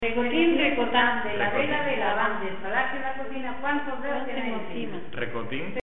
Materia / geográfico / evento: Canciones de juego Icono con lupa
Moraleda de Zafayona (Granada) Icono con lupa
Secciones - Biblioteca de Voces - Cultura oral